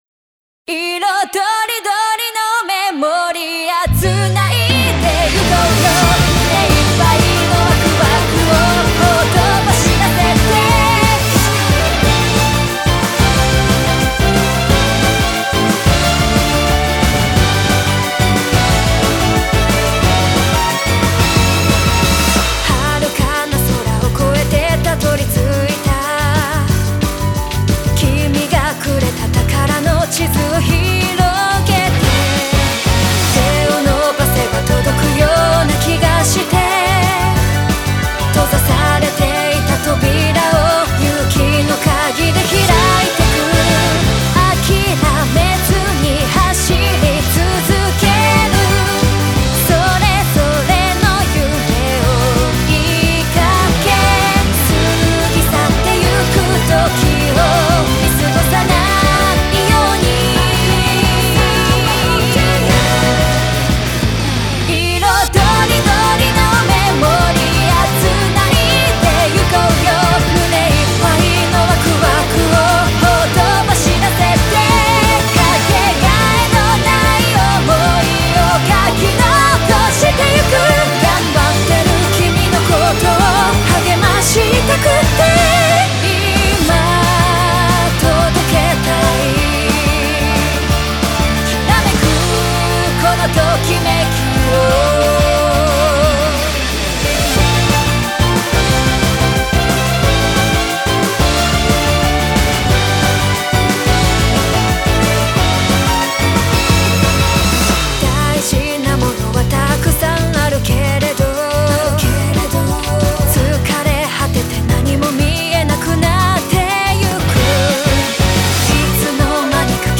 ・Vocal:宮舞モカ